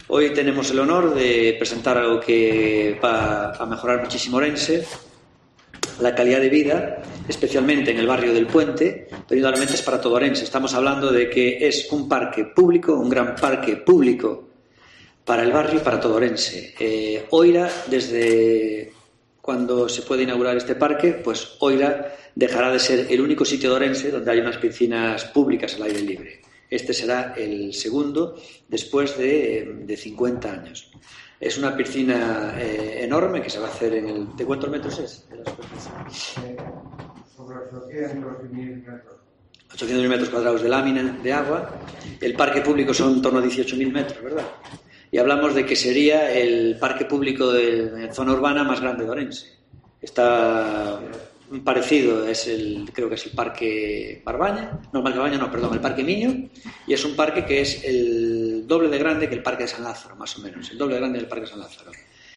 Declaraciones de Gonzalo Pérez Jácome sobre el futuro parque de A Ponte